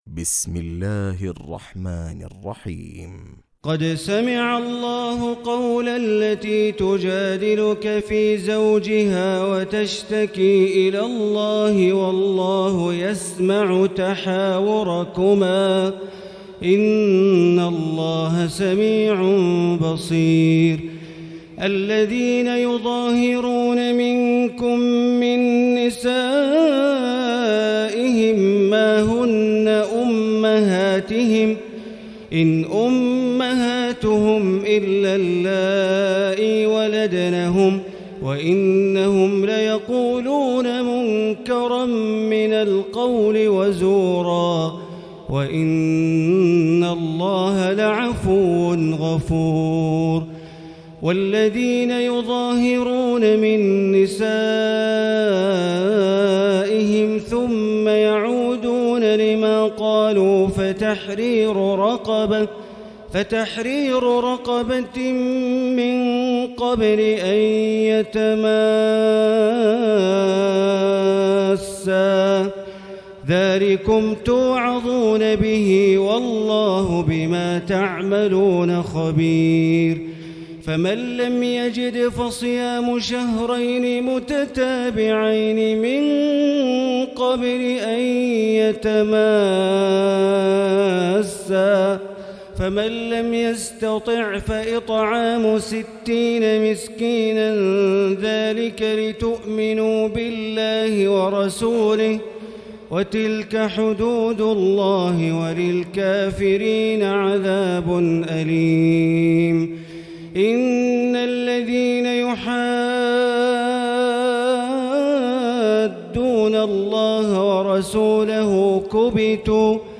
تراويح ليلة 27 رمضان 1439هـ من سورة المجادلة الى الصف Taraweeh 27 st night Ramadan 1439H from Surah Al-Mujaadila to As-Saff > تراويح الحرم المكي عام 1439 🕋 > التراويح - تلاوات الحرمين